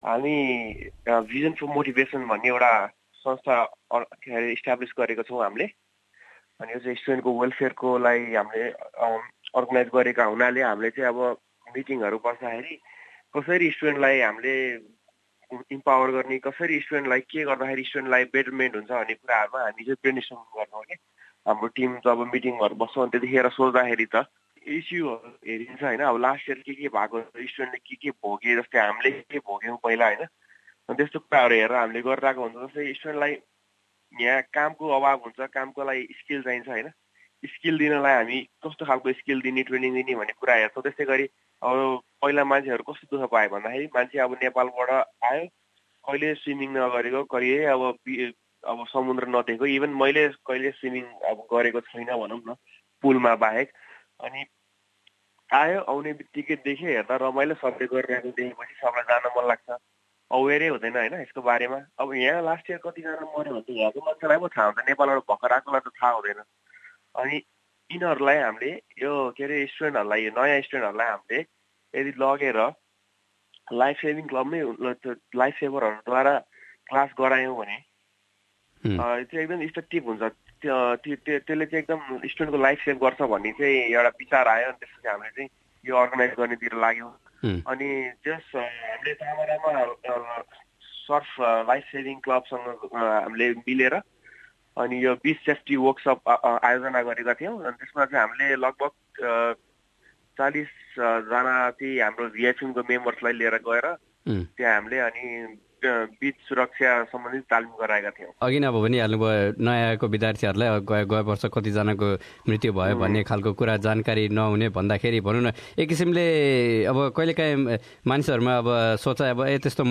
उनले उक्त विषयबारे एसबीएस नेपालीसँग गरेको कुराकानी।